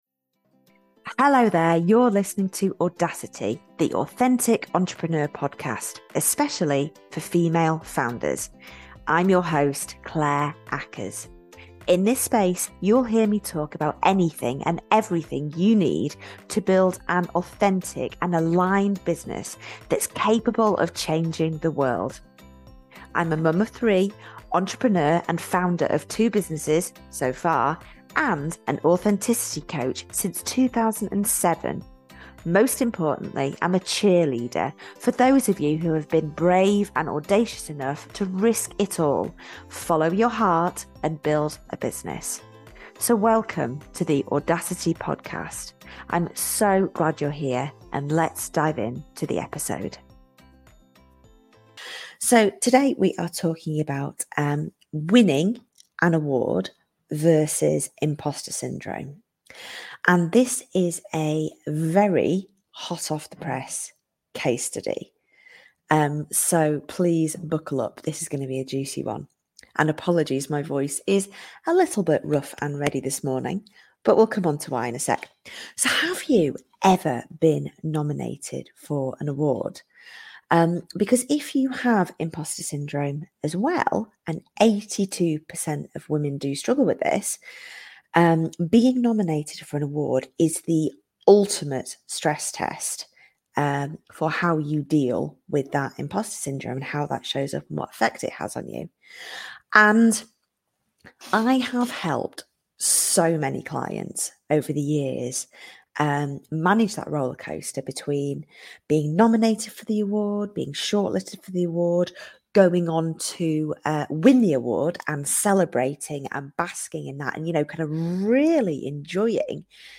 Buckle up, this is a raw and hot off-the-press conversation